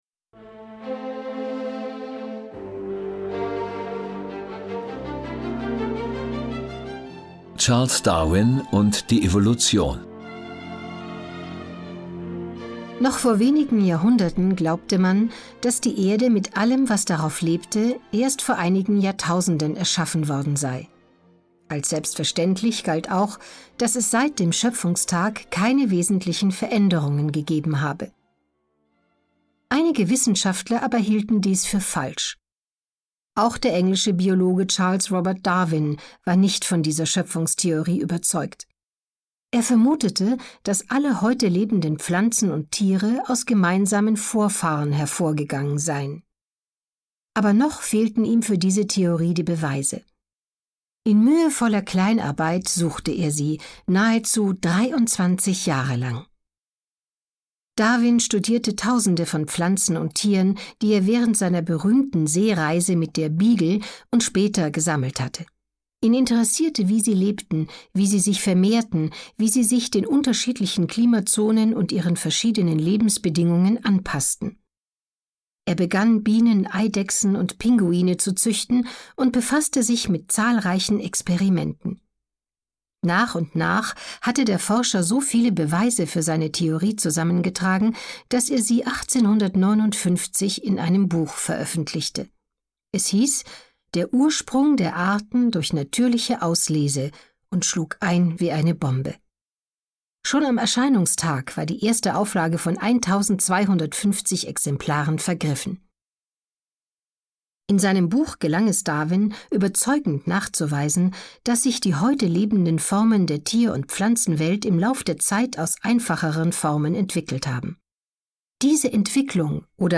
Hörbuch: CD WISSEN - Allgemeinbildung.